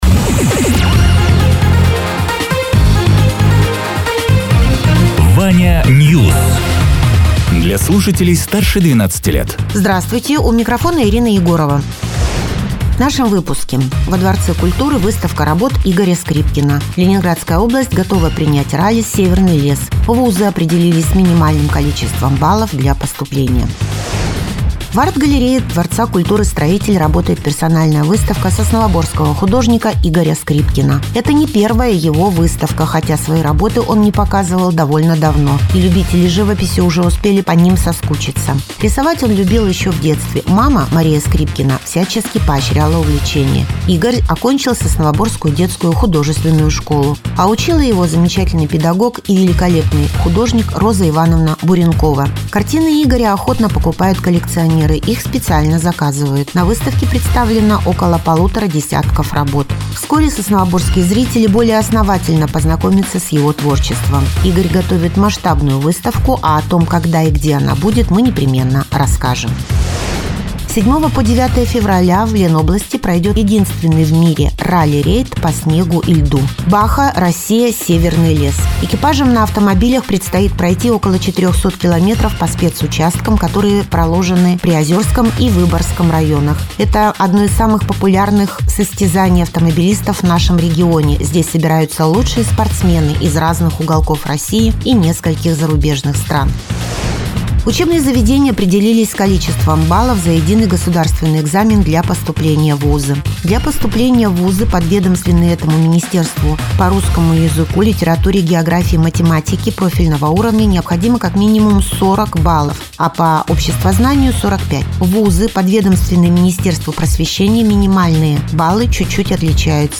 Радио ТЕРА 22.01.2025_10.00_Новости_Соснового_Бора